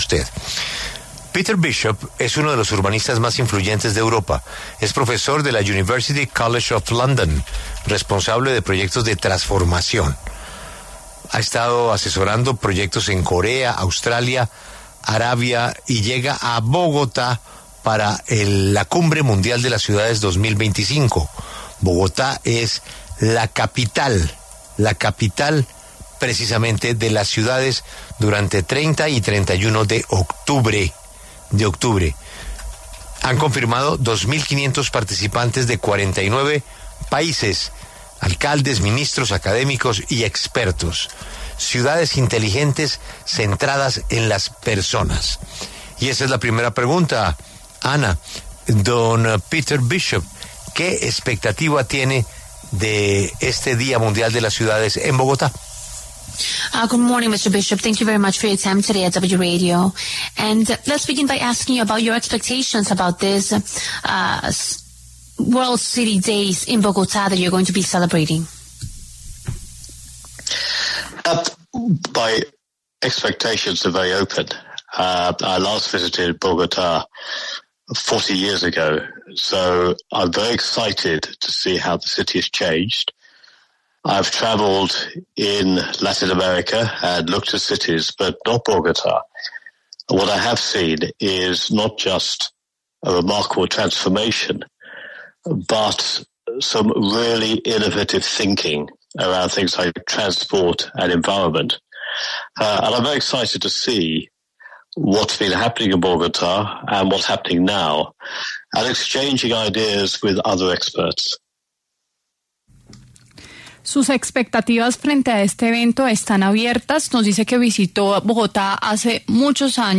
pasó por los micrófonos de La W para hablar sobre este evento.